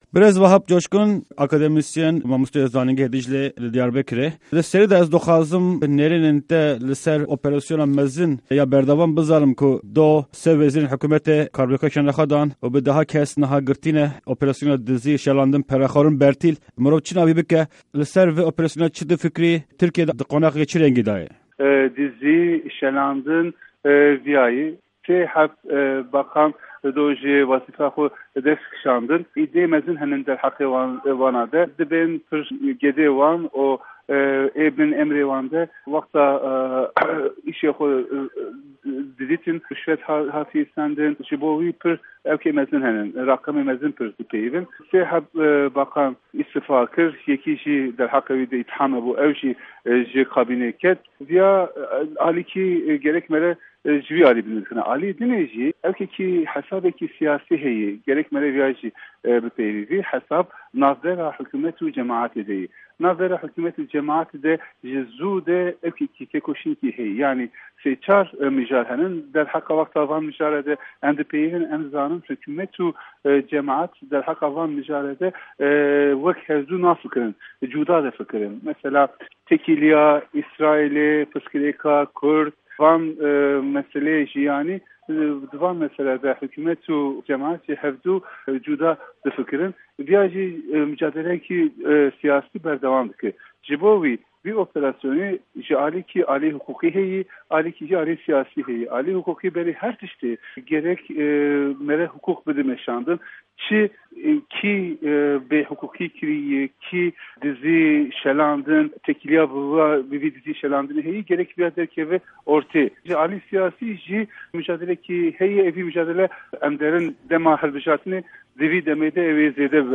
Di hevpeyvîneke Pişka Kurdî de